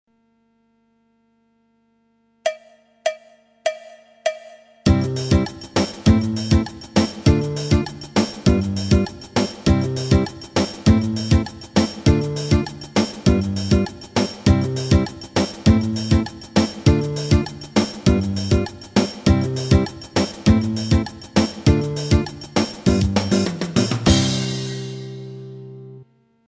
Il se joue quant à lui d'une manière assez rapide avec triangle, zabumba, shaker.
Variante 3 figure guitare baião 3.